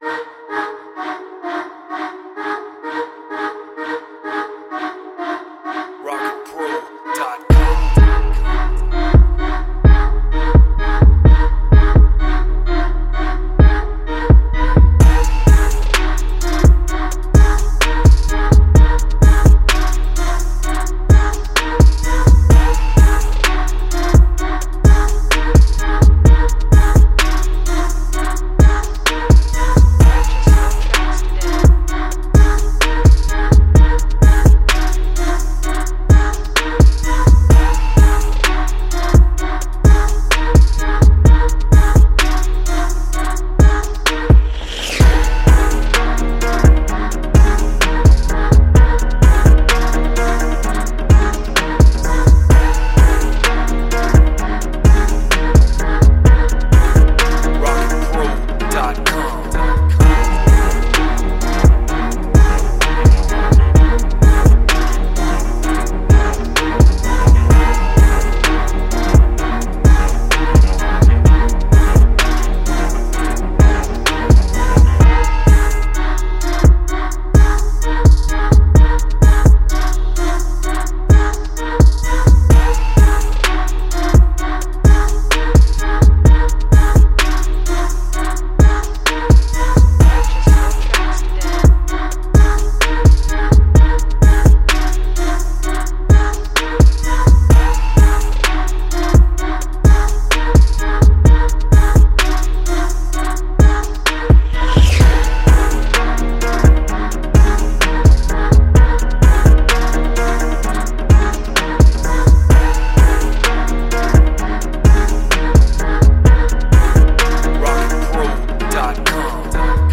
128 BPM.
with choirs, organs, and hard 808s.